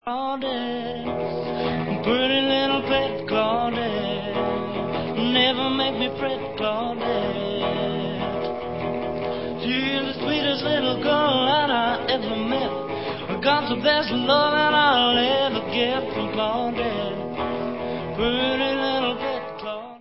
vocal/guitar demo